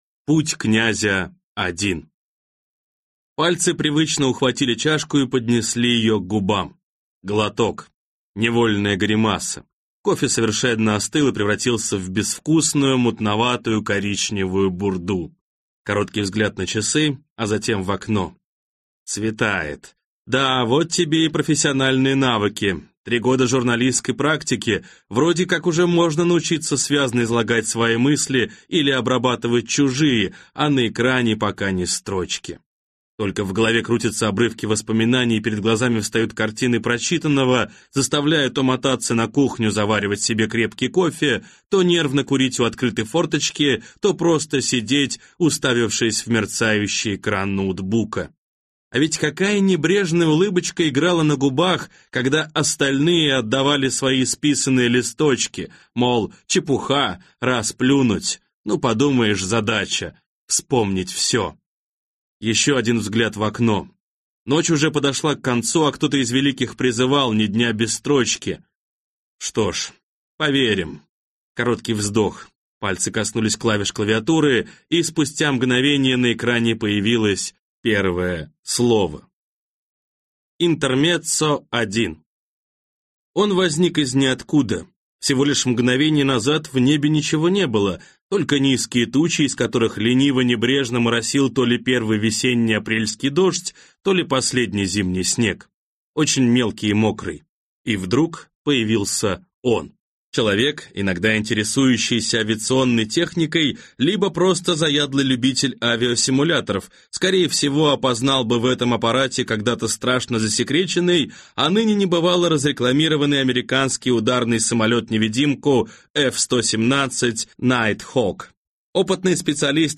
Аудиокнига Атака на будущее | Библиотека аудиокниг
Прослушать и бесплатно скачать фрагмент аудиокниги